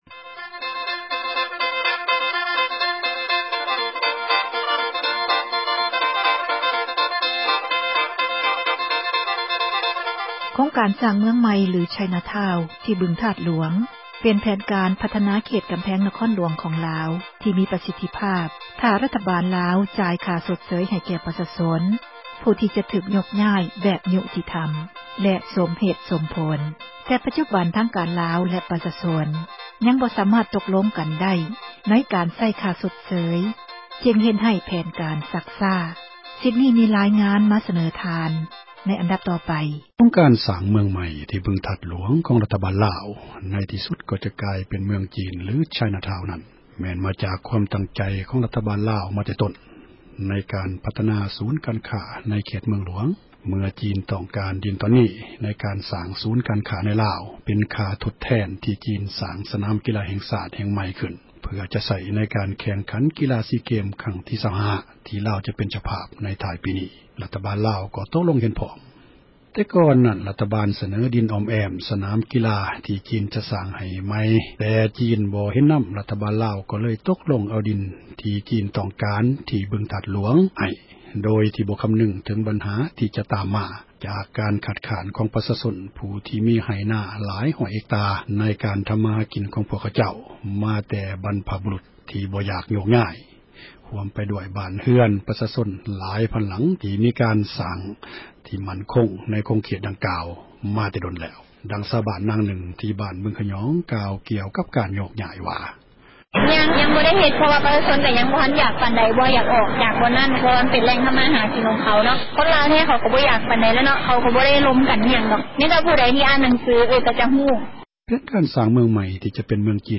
ໂຄງການສ້າງ ເມືອງຈີນ ທີ່ບຶງທາດຫລວງ — ຂ່າວລາວ ວິທຍຸເອເຊັຽເສຣີ ພາສາລາວ